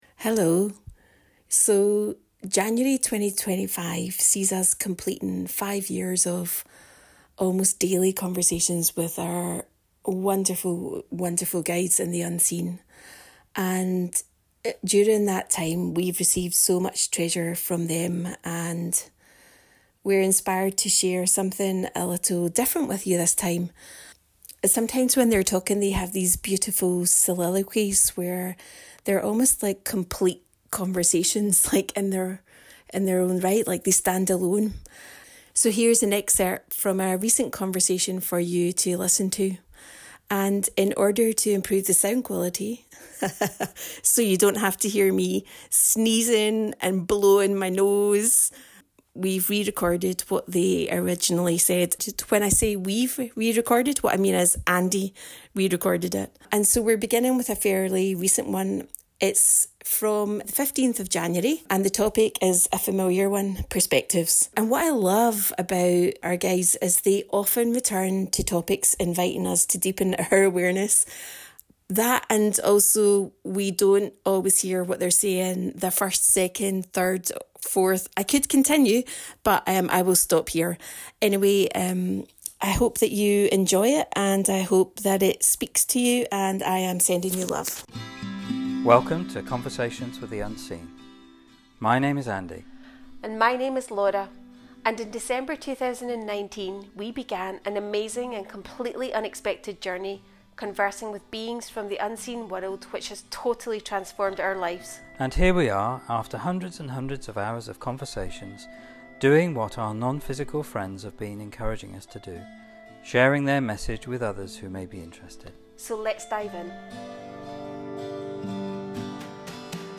Here's a conversation revisiting the topic of perspectives. Perspectives can be a way to expand our awareness and live a life more aligned with our true nature.